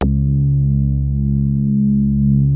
P.5 C#3 8.wav